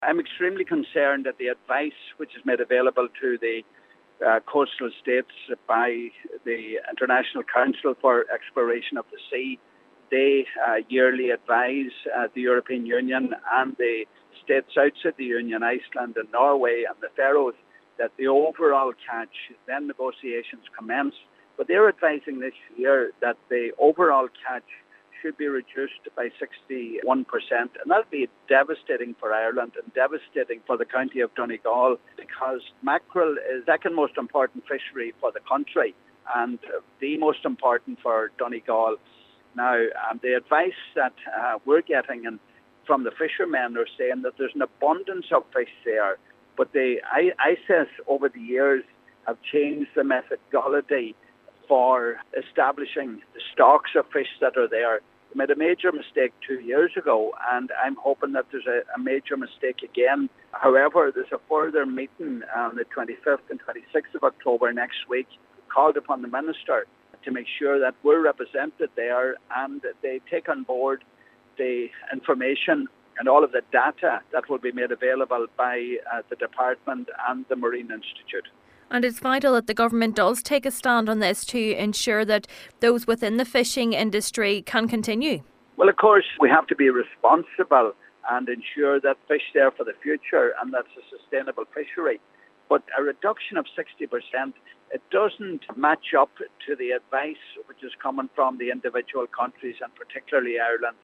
Donegal Deputy Pat the Cope Gallagher believes the figures presented are flawed: